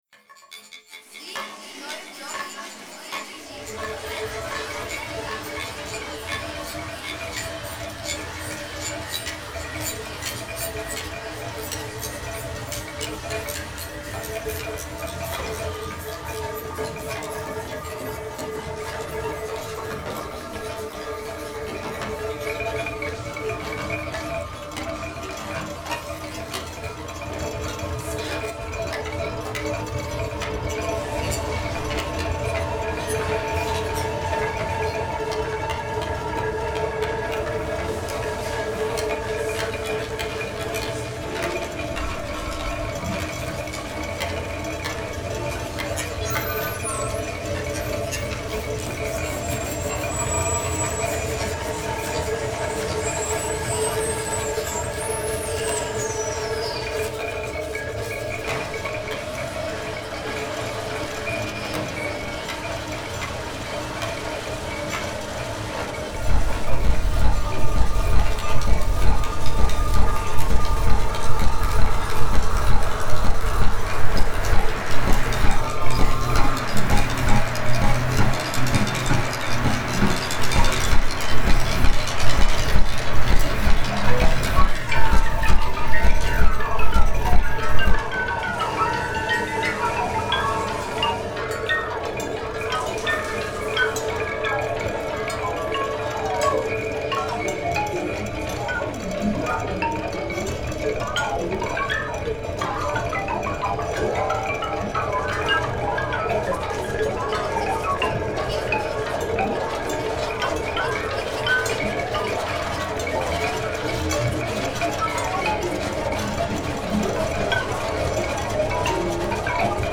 The Sun and Moon played Tug of War over the light and darkness. We were assisting, phasing the reversible interlude, twilighting over the mountains where devoted farmers secretly ignited the pure soundscape of physical strength and passion.